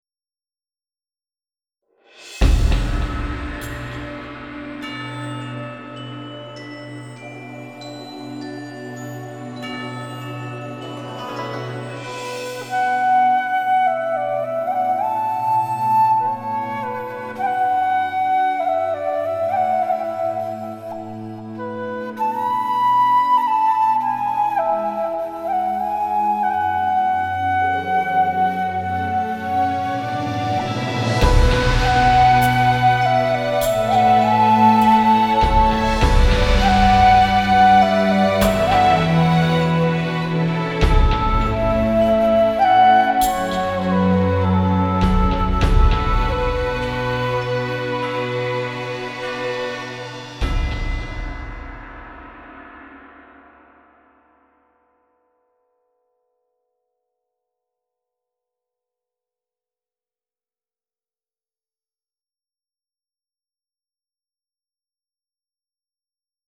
类型 配乐
整个配乐吸收了昆曲曲牌的音乐元素 结合现代电子乐与传统民乐
创造出或婉转 或哀伤 或古朴 或悠远的意